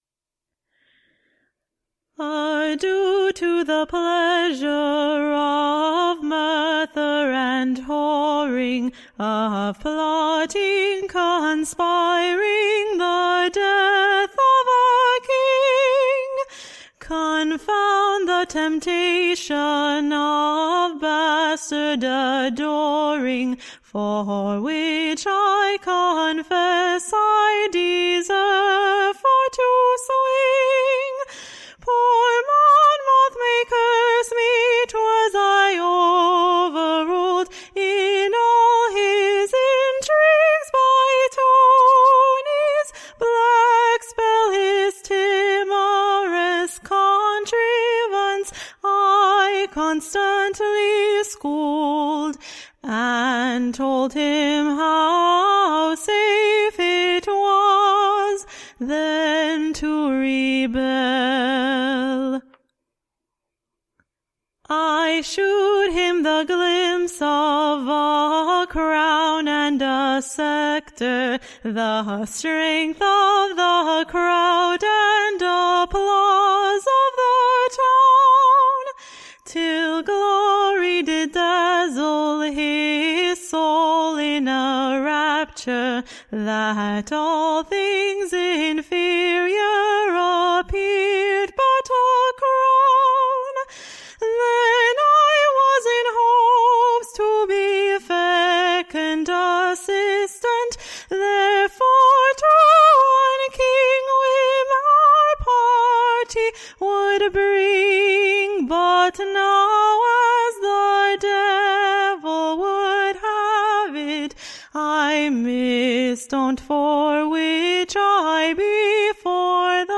Recording Information Ballad Title Sr. Thomas Armstrong's / Last Farewell to the / VVORLD: / He being Condemned for HIGH-TREASON, and Conspiring the Death of the / KING and the DUKE, / and subverting the Government of these three Kingdoms / A / SONG.